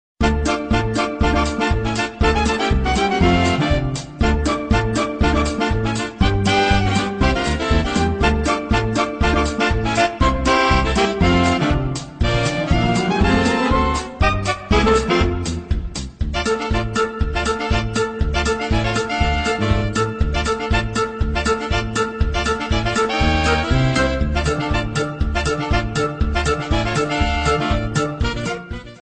Jazz Ringtones